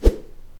attack animation sounds
swoosh-03.ogg